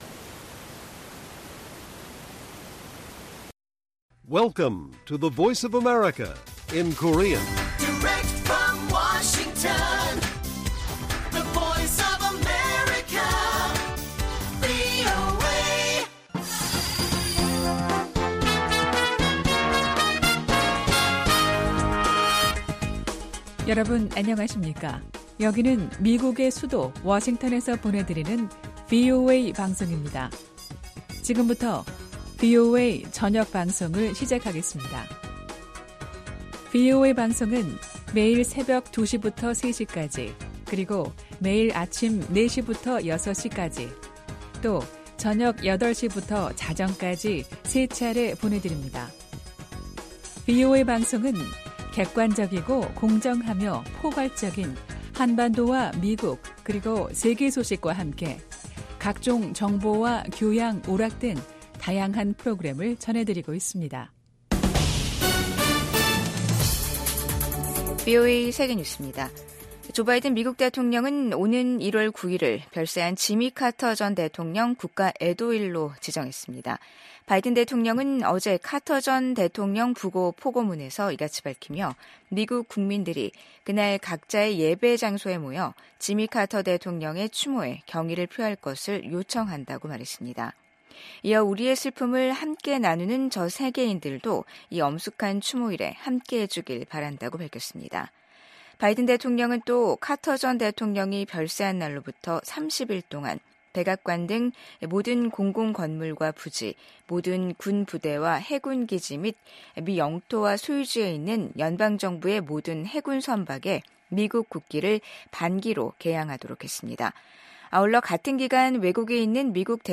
VOA 한국어 간판 뉴스 프로그램 '뉴스 투데이', 2024년 12월 30일 1부 방송입니다. 김정은 북한 국무위원장은 연말 노동당 전원회의에서 미국에 대해 최강경 대응전략을 천명했지만 구체적인 내용은 밝히지 않았습니다. 러시아에 파병된 북한군이 무모한 인해전술을 펼치면서 지난주에만 1천명이 넘는 사상자가 발생했다고 미국 백악관이 밝혔습니다. 미국 국무부는 한국의 새 대통령 대행과도 협력할 준비가 돼 있다고 밝혔습니다.